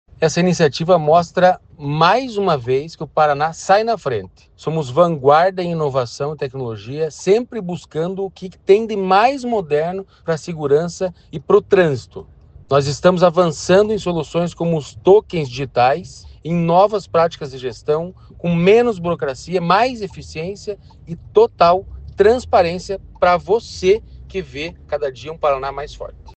Sonora do diretor-presidente do Detran-PR, Santin Roveda, sobre o fórum de tokenização veicular